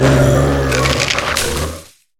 Cri de Courrousinge dans Pokémon HOME.